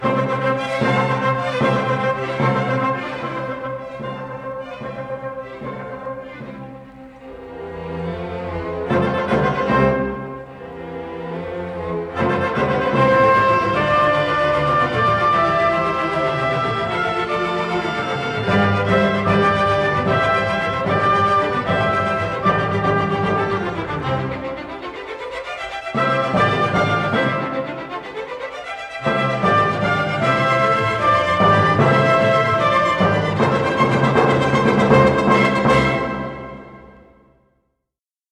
This is the Presto in a performance by the